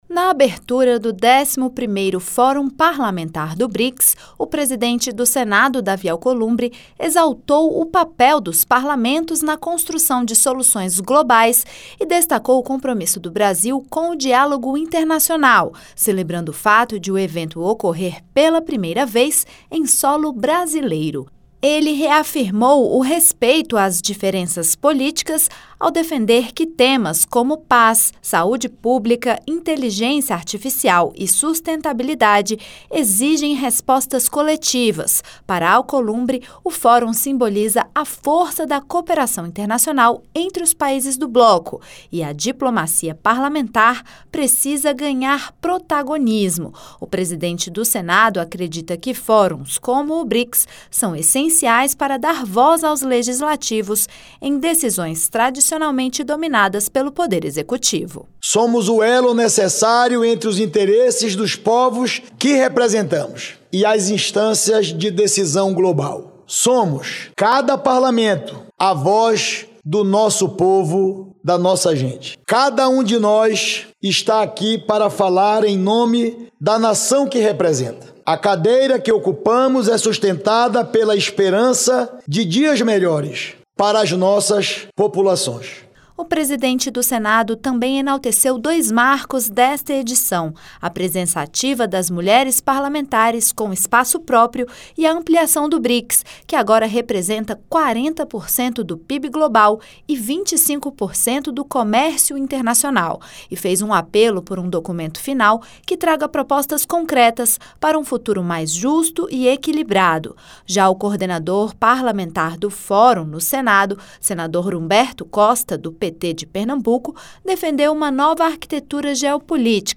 Na abertura do 11º Fórum Parlamentar do Brics, o presidente do Senado, Davi Alcolumbre, defendeu o protagonismo dos parlamentos e o diálogo como caminho para enfrentar desafios globais como paz, saúde e inteligência artificial.